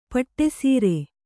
♪ paṭṭe sīre